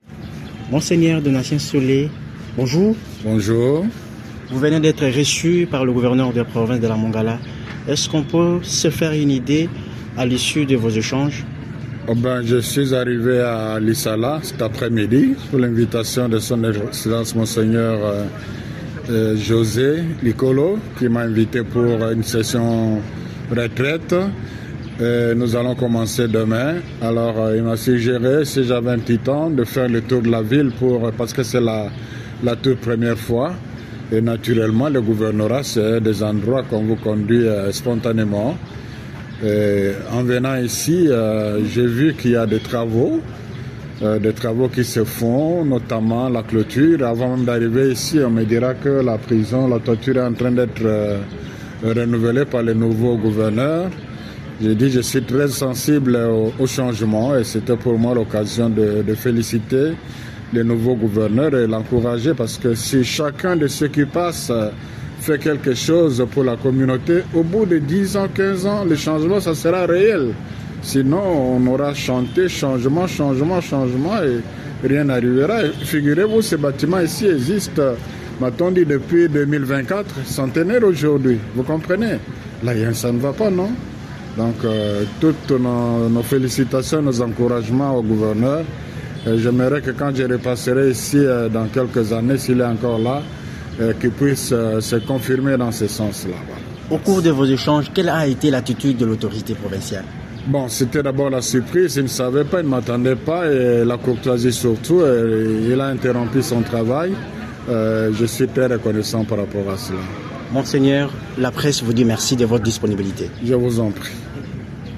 Ci-dessous, l’interview de Mgr Donatien Nshole à la presse de Lisala :
Interview-Mgr-Donatien-Shole-.mp3